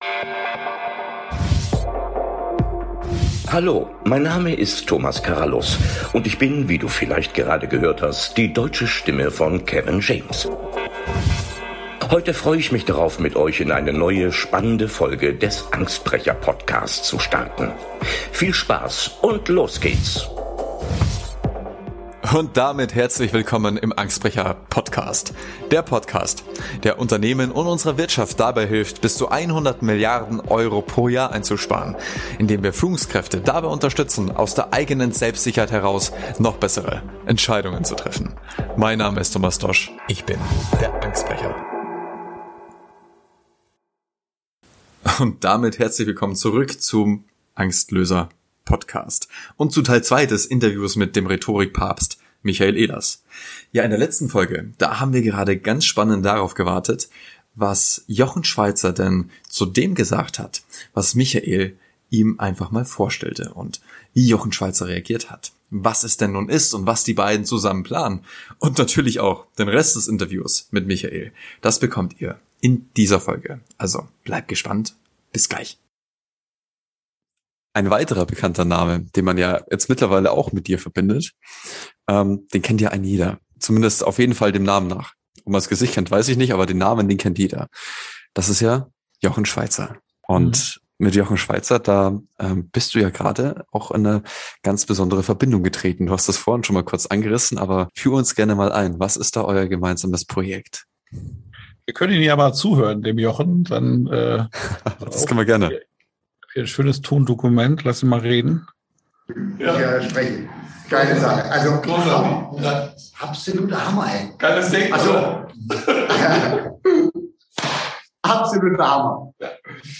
Mein heutiger Gast ist nicht nur extrem erfolgreicher Unternehmer und ein grandioser Netzwerker, sondern auch eine Person der man unheimlich gerne zuhört. Freut euch auf das Interview mit dem Rhetorik- Papst